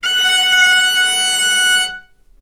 vc_sp-F#5-ff.AIF